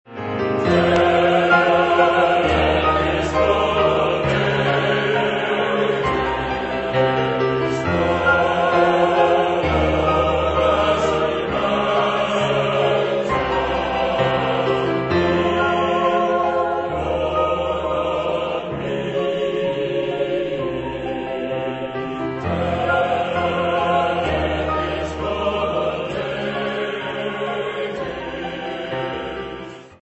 Type de choeur : SATB  (4 voix mixtes )
Solistes : Baryton (1)  (1 soliste(s))
Instruments : Piano (1)
Tonalité : fa mineur ; sol mineur